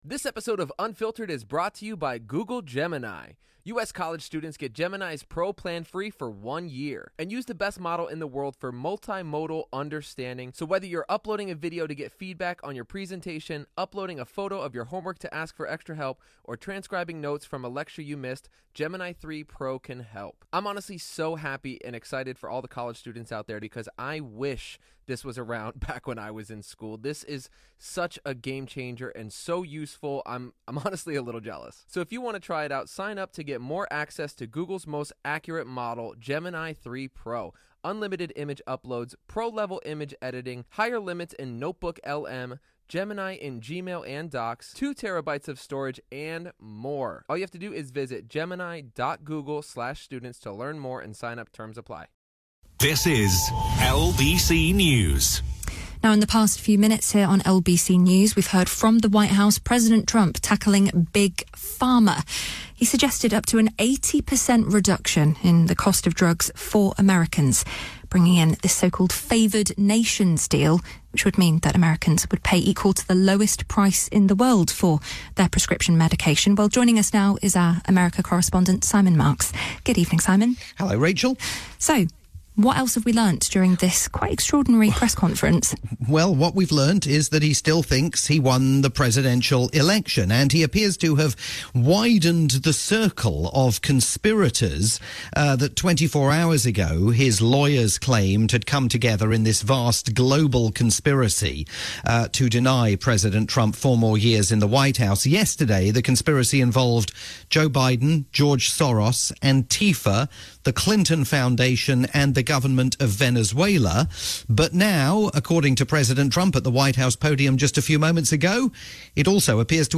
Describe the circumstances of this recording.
live report for LBC News